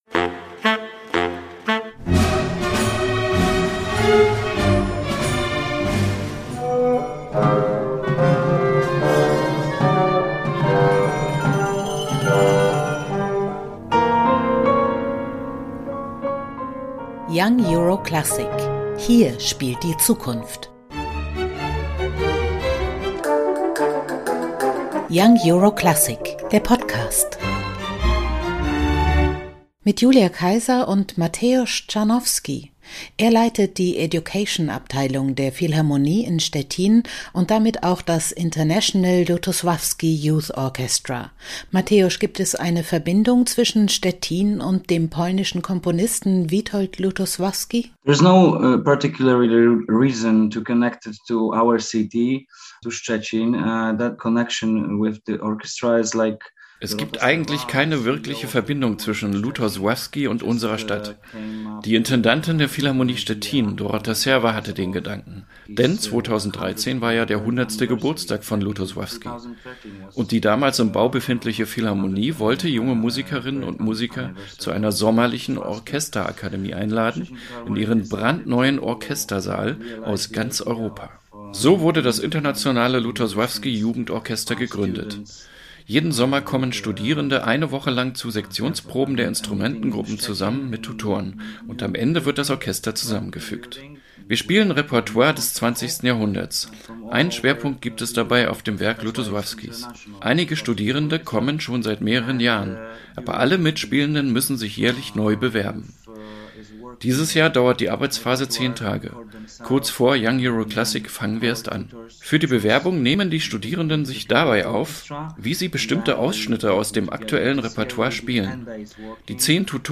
Konzerteinführung 17.08.2022 | International Lutosławski Youth Orchestra